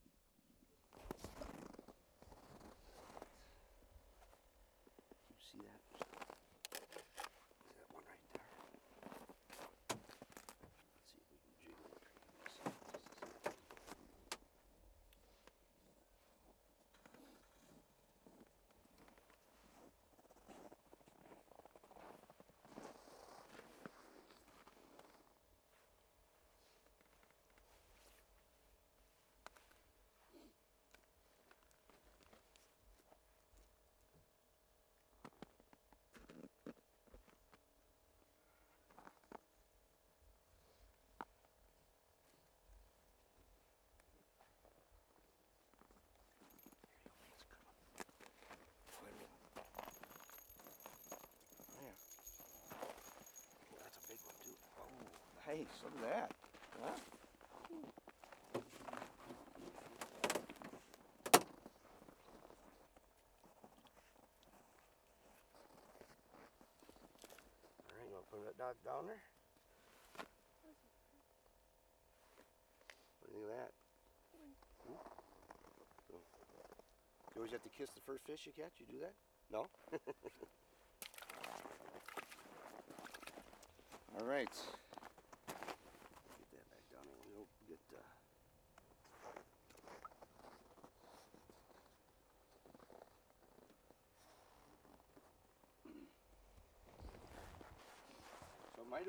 Index of /SkyCloud/Audio_Post_Production/Education/The Ways/2026-01-29 Ice Fishing Camp - Mole Lake/Day 3 - 1-31